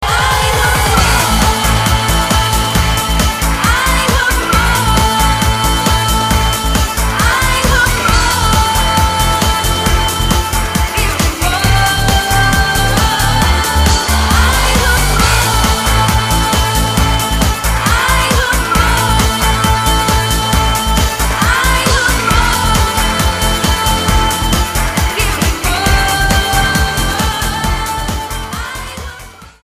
STYLE: Dance/Electronic
The music was uplifting.